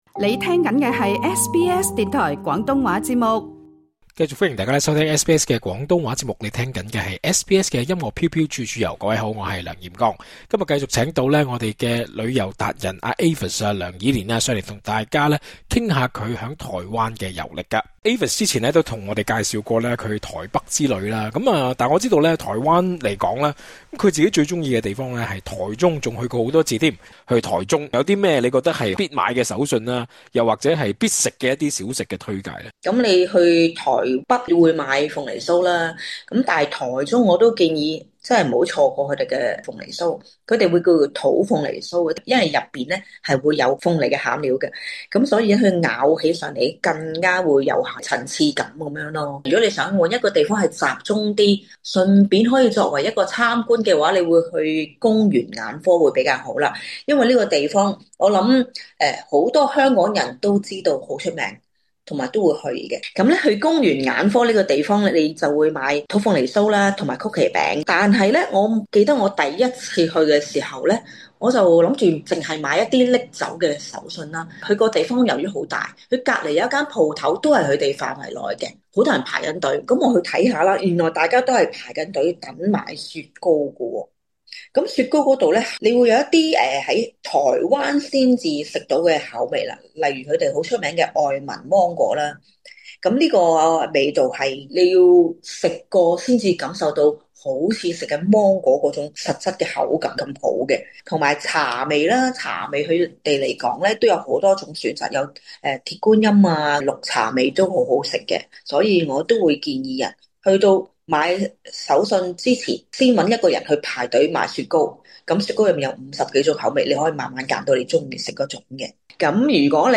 Credit: Facebook/宮原眼科 她亦會介紹在台中最知名的手信街，DIY 自製太陽餅做手信，感受傳統餅食製作的工藝。詳情請聽足本訪問： LISTEN TO 【音樂飄飄處處遊】台中古蹟如何變身文青聖地？